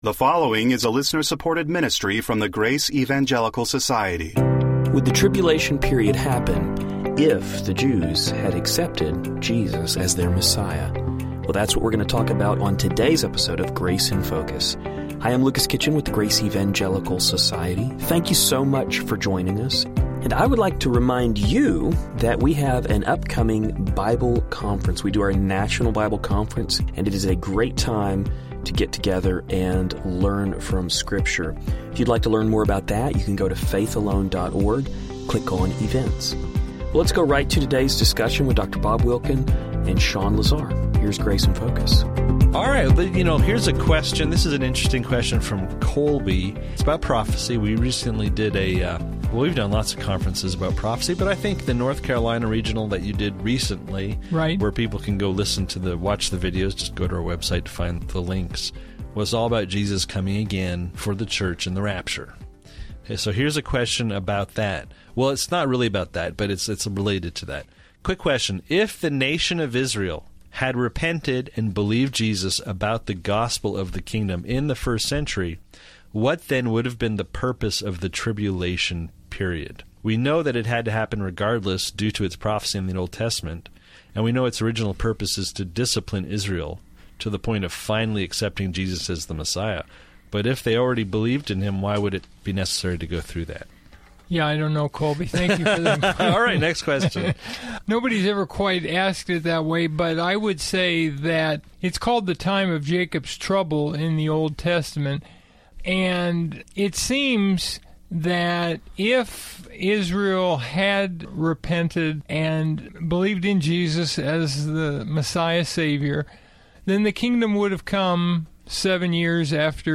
First, we will hear the guys discuss the tribulation period. Specifically, they will address what the tribulation would have looked like if the nation of Israel had accepted the Lord at His first coming.